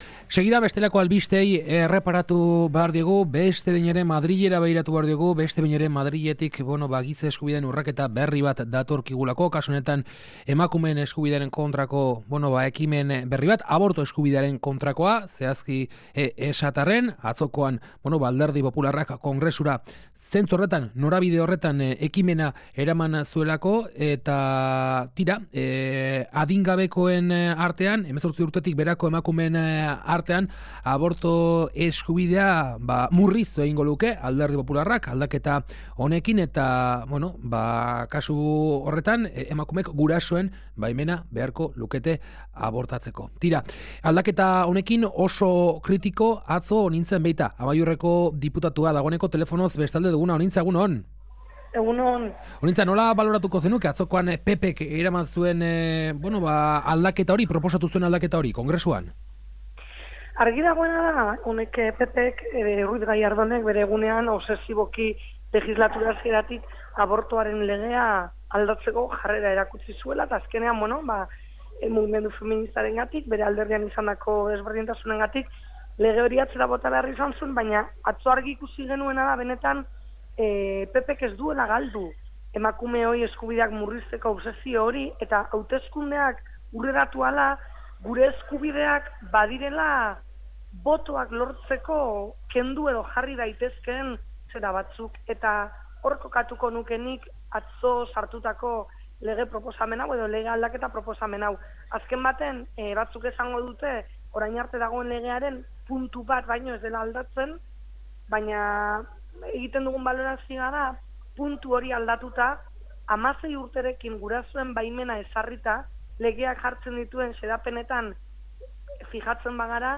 Emakumeen eskubideen ikuspegitik beraz, beste atzera pausu bat, gaur, Kalegorrian saioan, Onintza Enbeitarekin baloratu duguna. Amaiur-ko diputatuak salatu duenez, emakumeen eskubideak PPren interes elektoralen menpe daude.